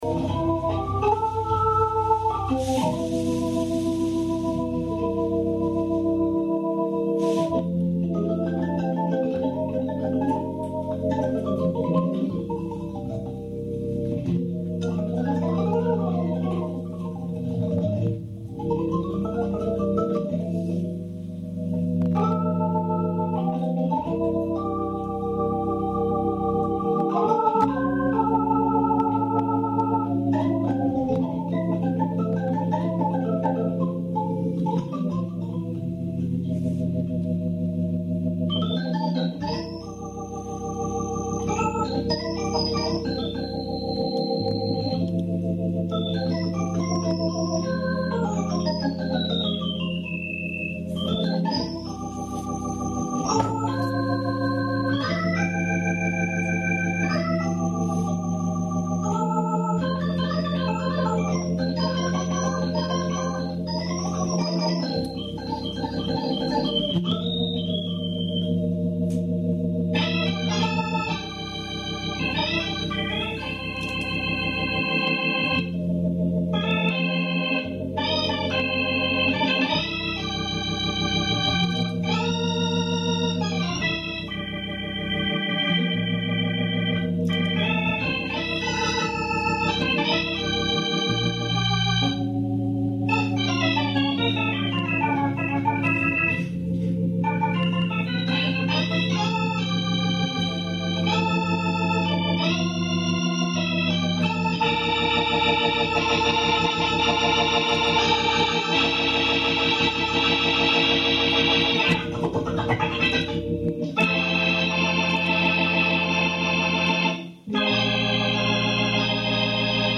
Un gentil spectateur a enregistré la totalité du concert de vendredi dernier sur un minidisc....la qualité n'est pas au rendez vous mais c'est ecoutable
guitare
batterie
La batterie couvre pas mal..mais bon, c'est du live!